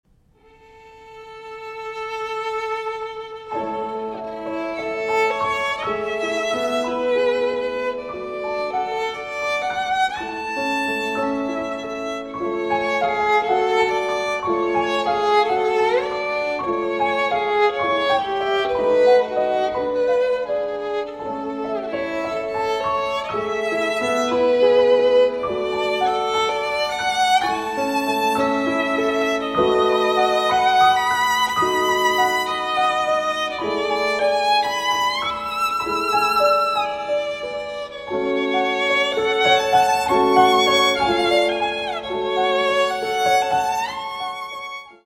Andante con espressione (6:06)